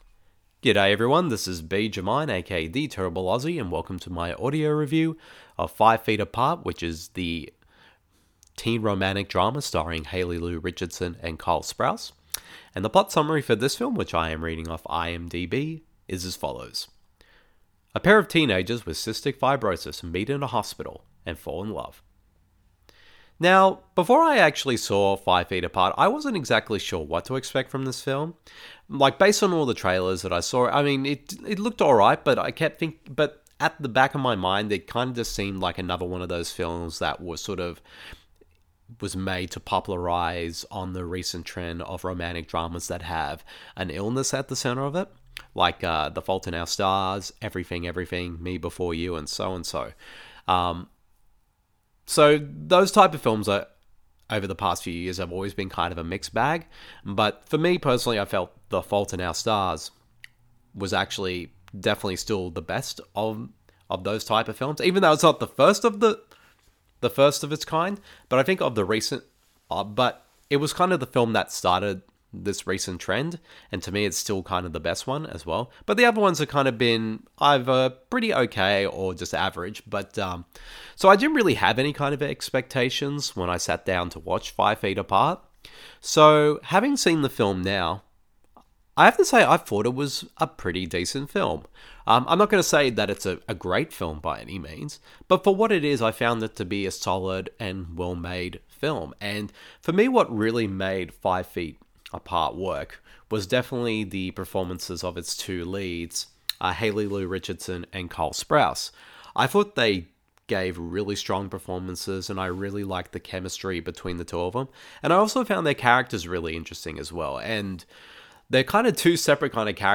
Audio review